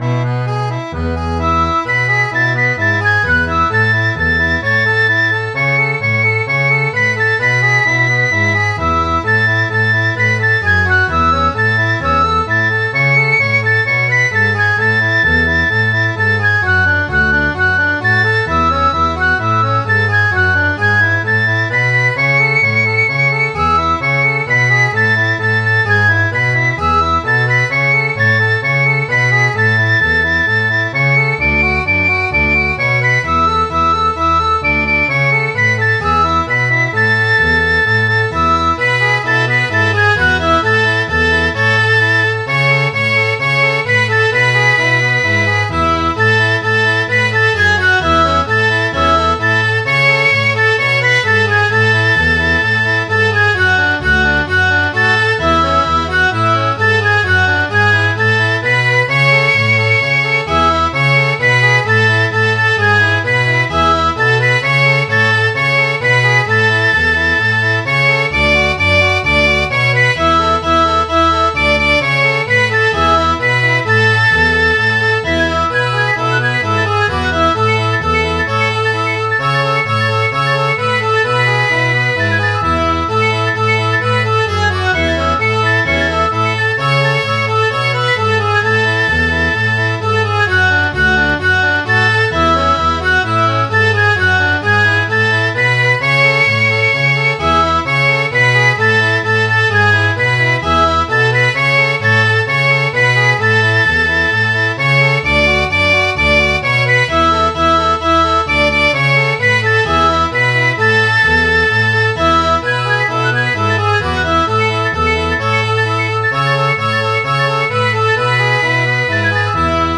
Midi File, Lyrics and Information to Rolling Down to Old Mohee